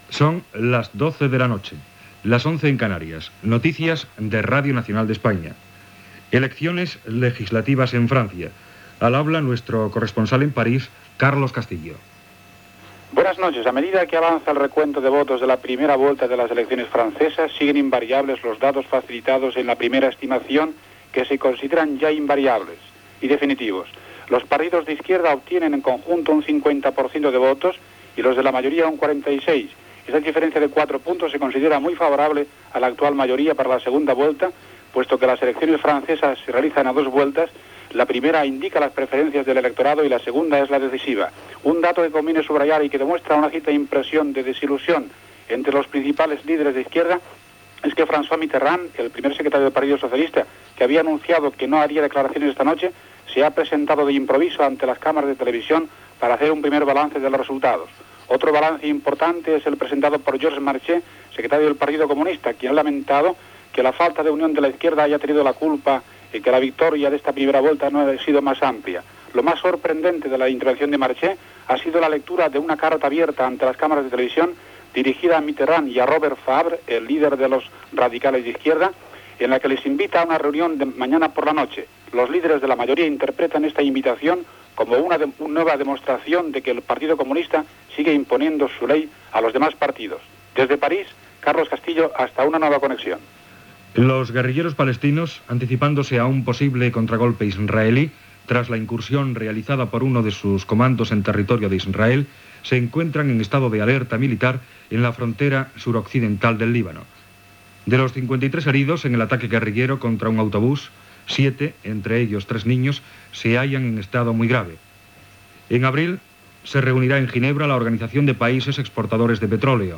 Indicatiu musical i veu de tancament.
Informatiu